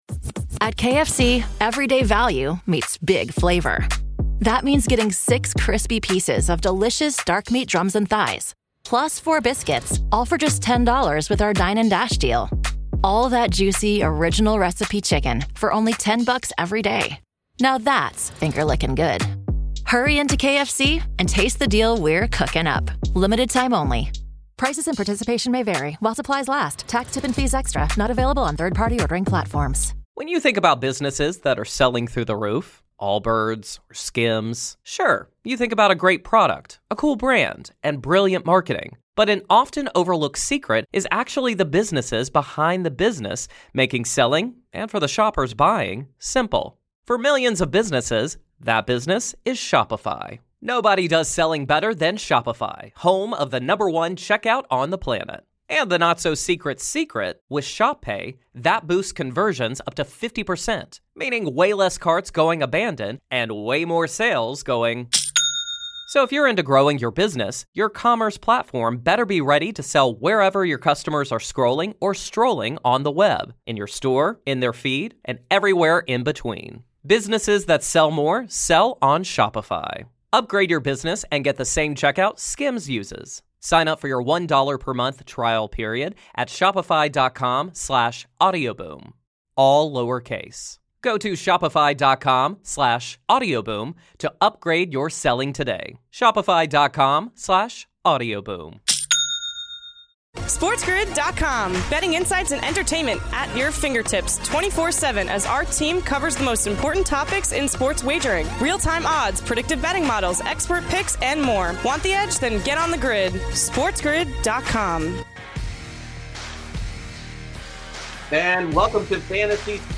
special guest and former MLB pitcher Brad Ziegler